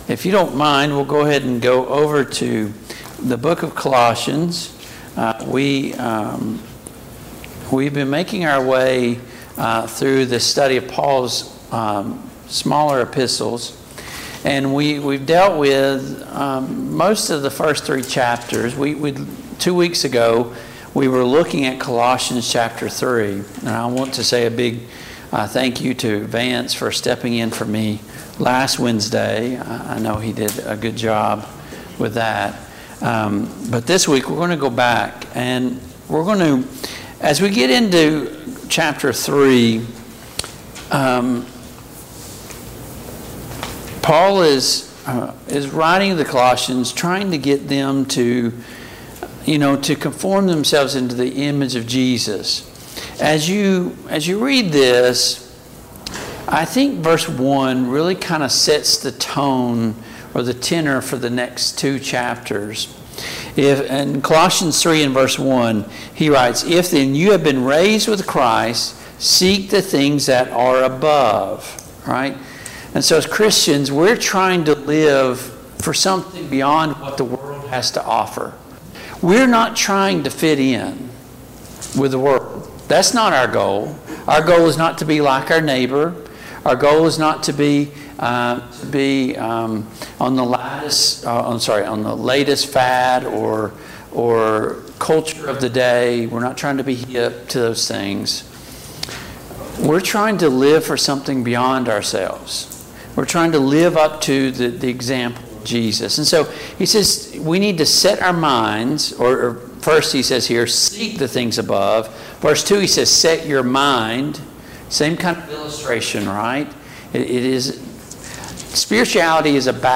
Passage: Colossians 3:12-21 Service Type: Mid-Week Bible Study Download Files Notes « 1.